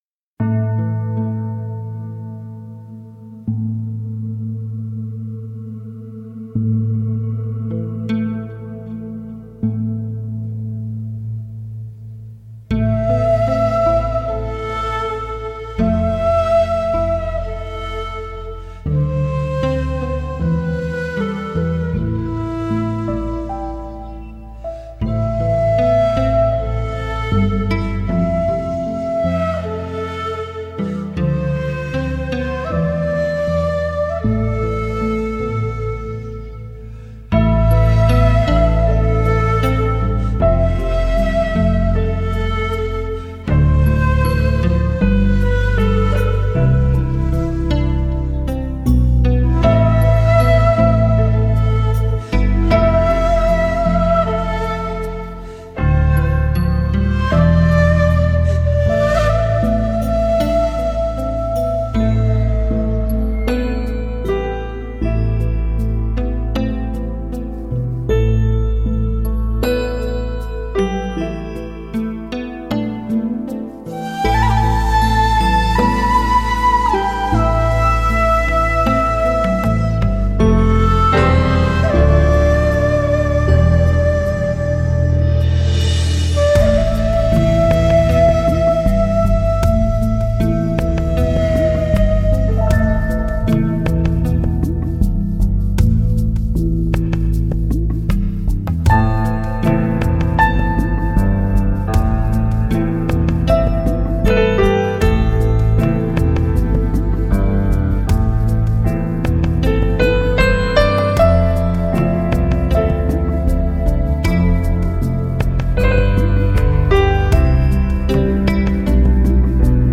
这张专辑不同于其他的尺八专辑，它的的配器十分丰富，很完美地将各个器乐连同电子乐结合在一起，听起来很有新意.